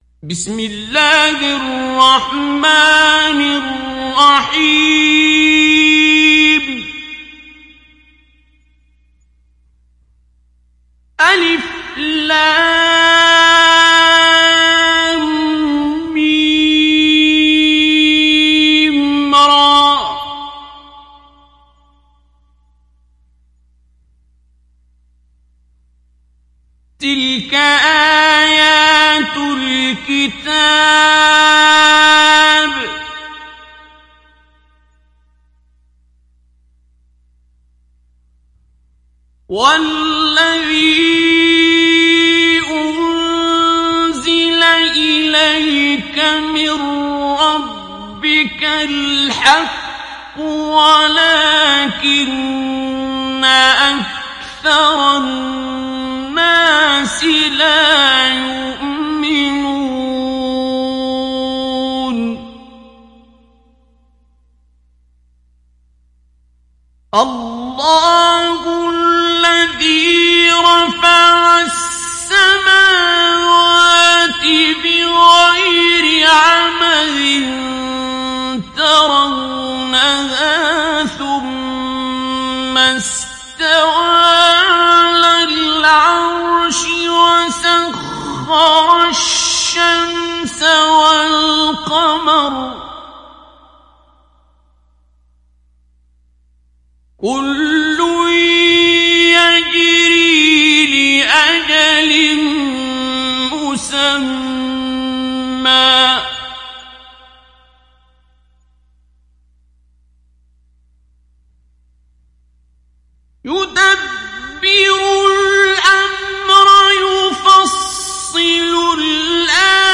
Download Surat Ar Rad Abdul Basit Abd Alsamad Mujawwad